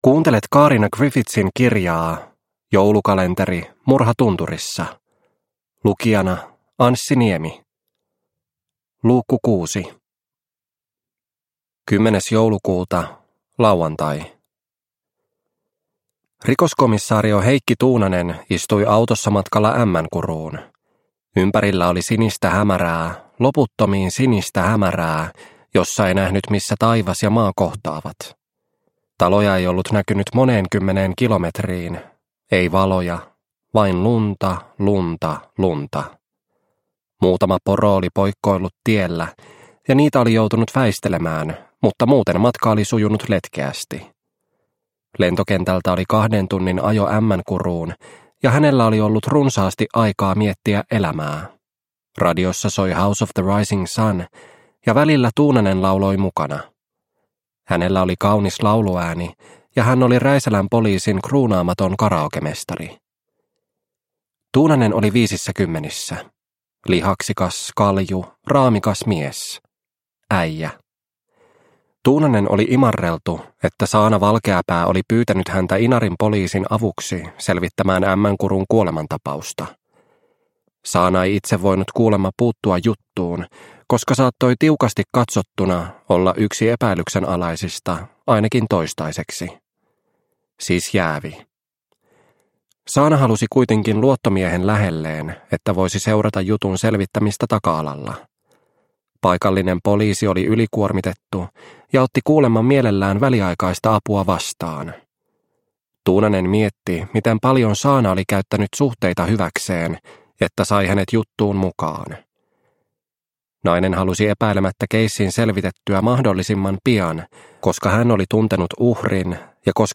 Murha tunturissa - Osa 6 – Ljudbok – Laddas ner